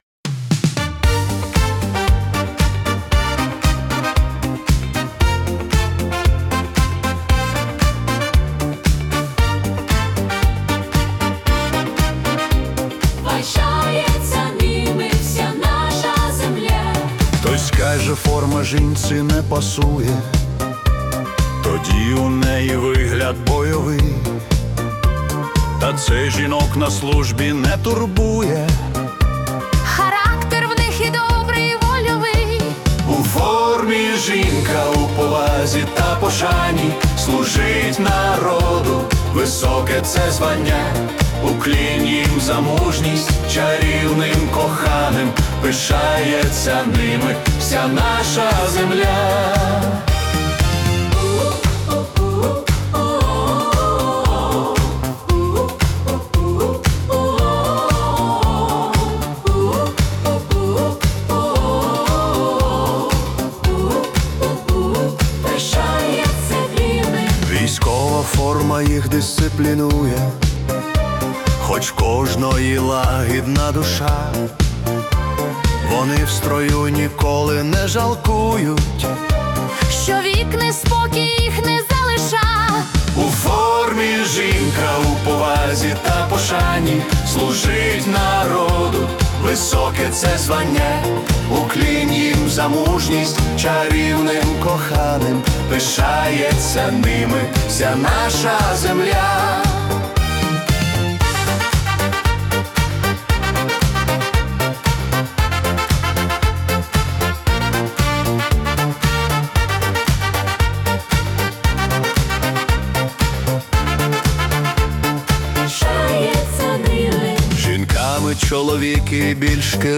🎵 Жанр: Italo Disco / Tribute
динамічна композиція у стилі Italo Disco (115 BPM)